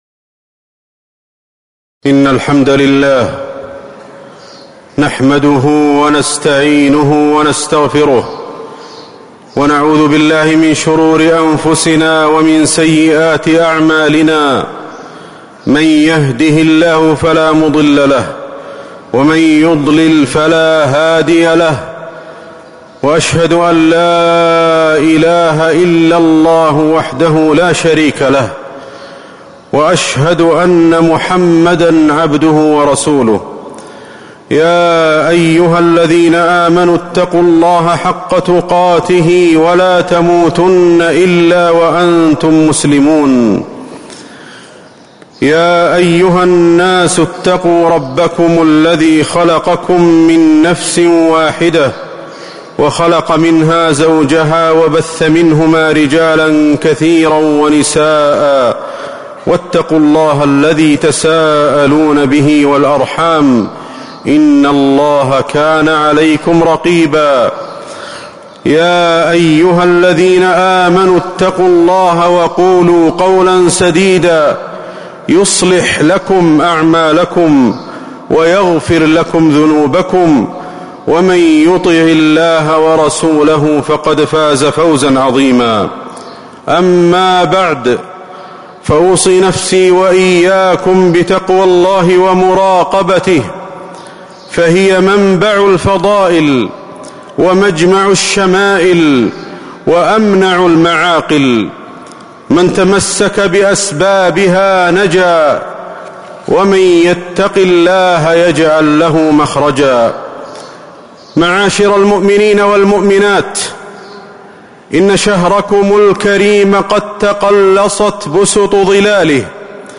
تاريخ النشر ٢٨ رمضان ١٤٤٦ هـ المكان: المسجد النبوي الشيخ: فضيلة الشيخ أحمد بن علي الحذيفي فضيلة الشيخ أحمد بن علي الحذيفي وداع رمضان